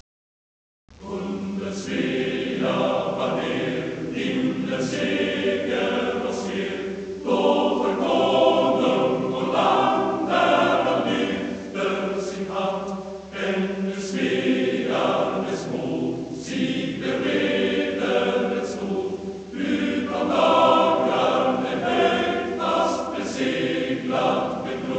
Marche för Studerande i Upsala
Teologiprofessorn Ödmann skrev texten till den första svenska manskörssången, med musik av Director Musices. Musiken hade skrivits tidigare som soldatkör till Hæffners opera Renaud (1801).